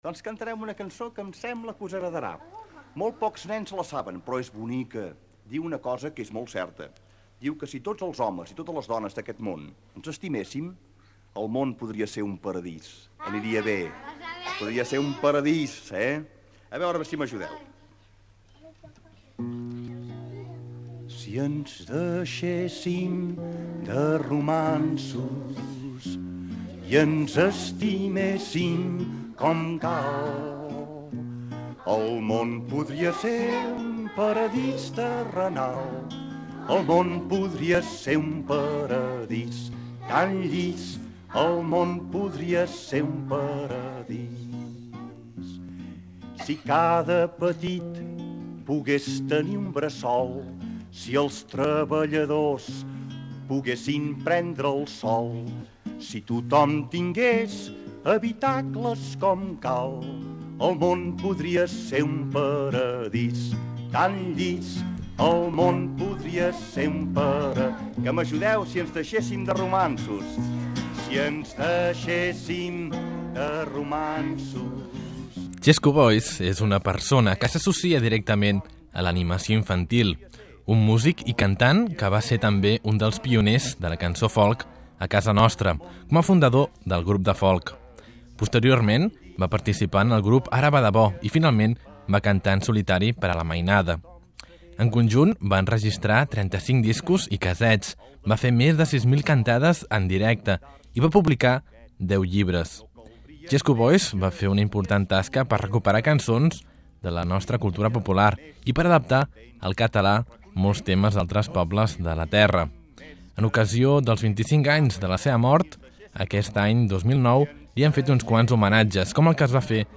Desde el Mundo Abierto de Radio L'H también hemos querido recordar la figura de Xesco con uno de sus mejores legados, la música infantil. Puede escuchar, entre otras piezas, como canta y presenta a los niños la famosa Guantanamera.
REPORTAJE sobre Xesco Boix